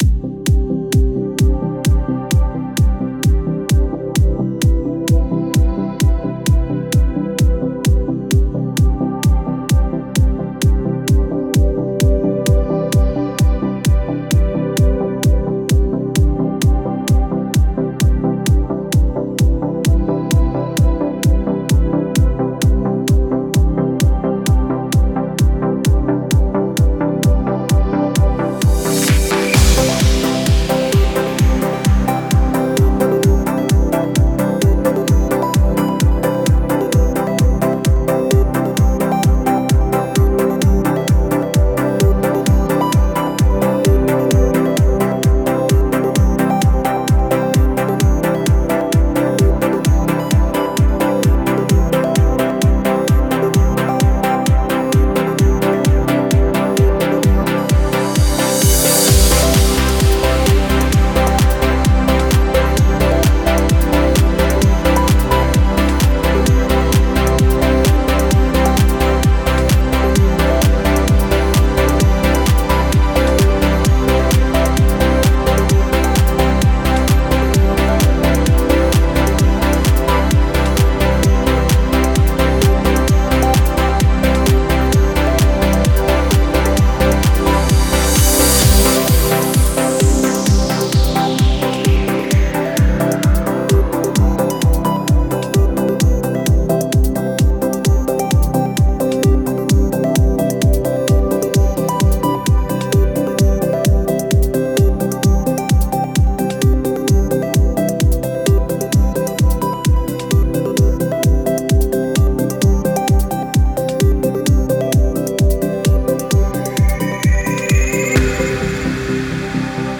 2020 play_arrow Layers of harmony built upon each other. Rhythms intermingling in progression.